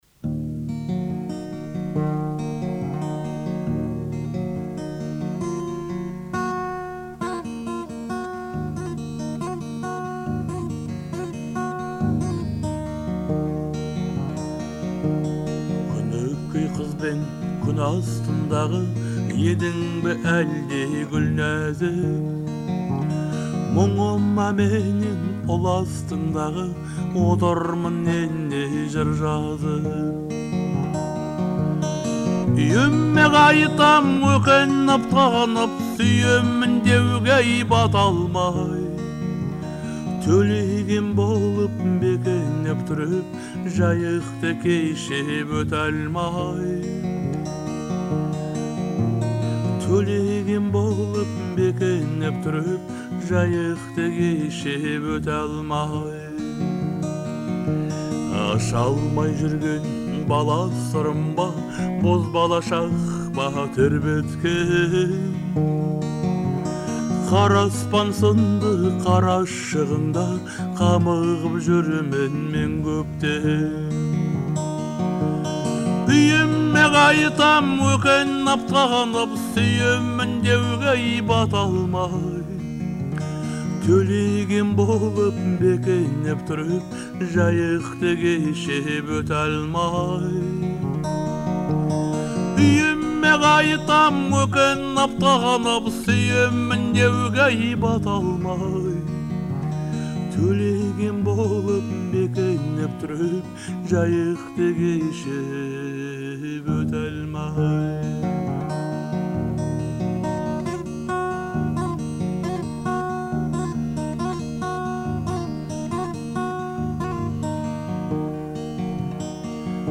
это романтическая казахская песня